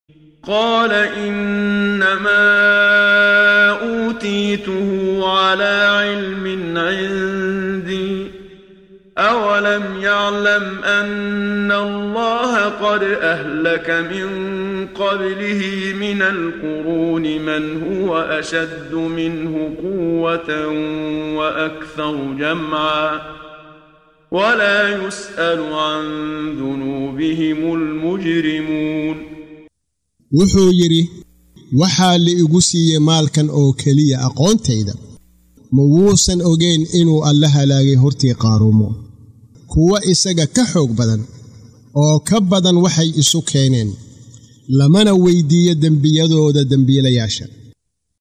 Waa Akhrin Codeed Af Soomaali ah ee Macaanida Suuradda Al-Qasas ( Qisooyinka ) oo u kala Qaybsan Aayado ahaan ayna la Socoto Akhrinta Qaariga Sheekh Muxammad Siddiiq Al-Manshaawi.